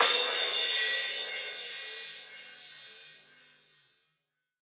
DnBCymbalA-03.wav